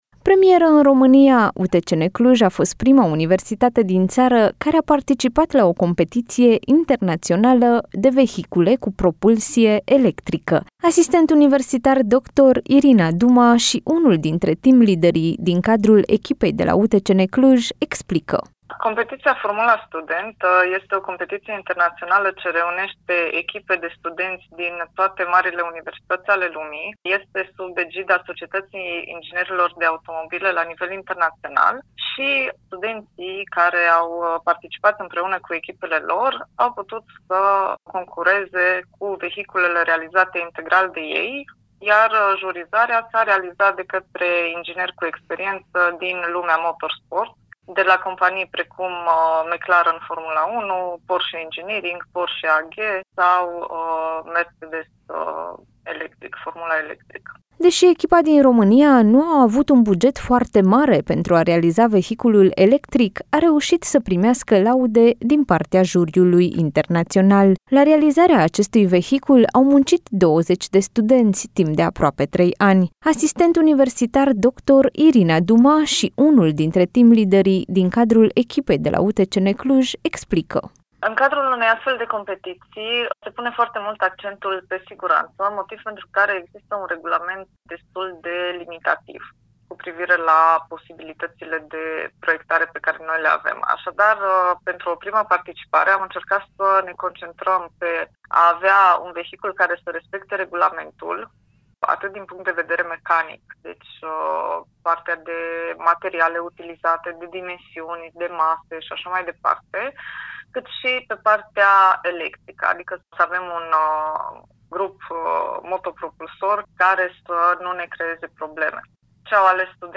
a stat de vorbă despre acest proiect